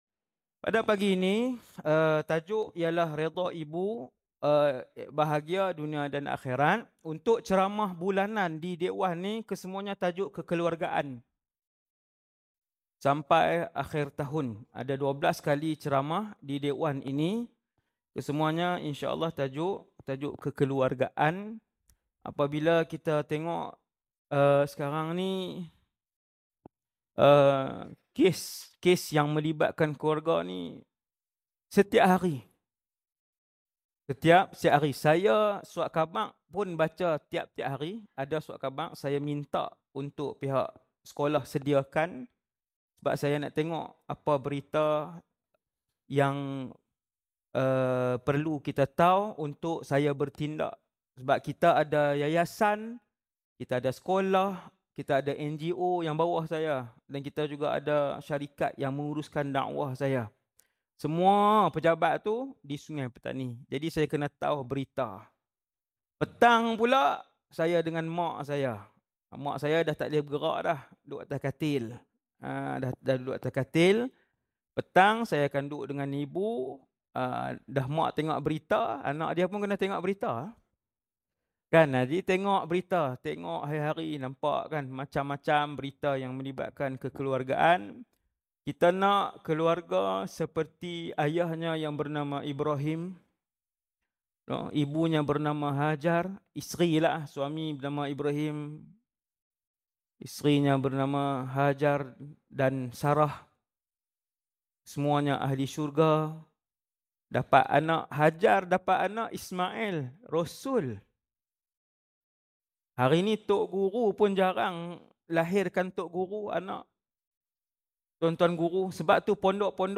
Ceramah Penuh - Reda Ibu, Bahagia Dunia Akhirat